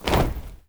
See You Again Stomp.wav